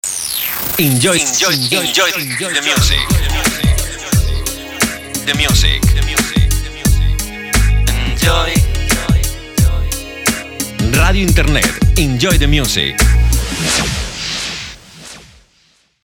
Si lo prefiere podemos realizar el indicativo con efectos de sonido :